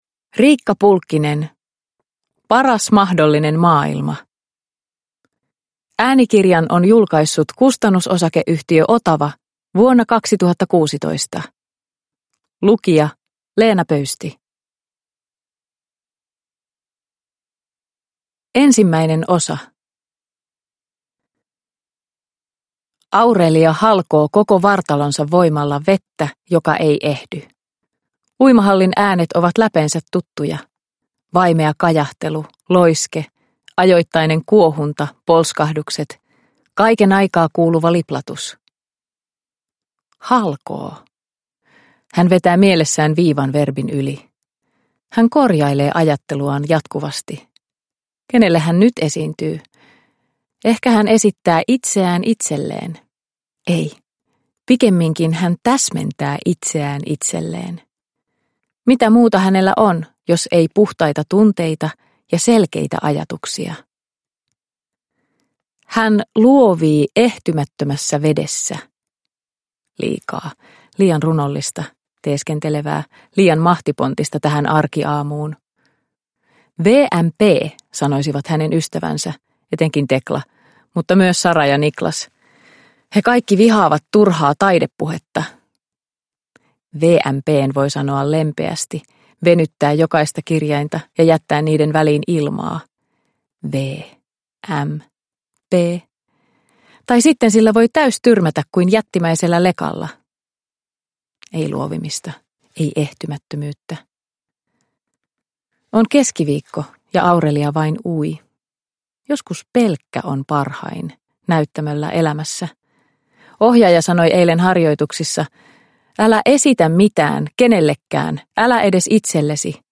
Paras mahdollinen maailma – Ljudbok – Laddas ner